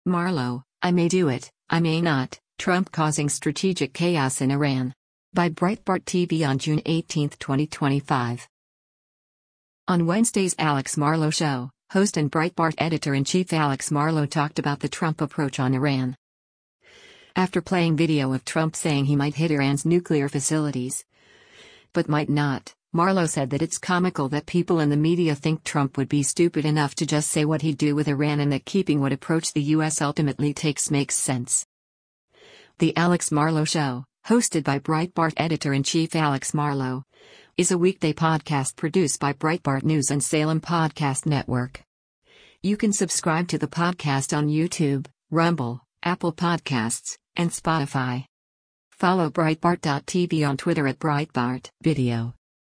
On Wednesday’s “Alex Marlow Show,” host and Breitbart Editor-in-Chief Alex Marlow talked about the Trump approach on Iran.
After playing video of Trump saying he might hit Iran’s nuclear facilities, but might not, Marlow said that it’s comical that people in the media think Trump would be stupid enough to just say what he’d do with Iran and that keeping what approach the U.S. ultimately takes makes sense.